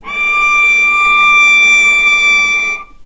healing-soundscapes/Sound Banks/HSS_OP_Pack/Strings/cello/ord/vc-D6-mf.AIF at 48f255e0b41e8171d9280be2389d1ef0a439d660
vc-D6-mf.AIF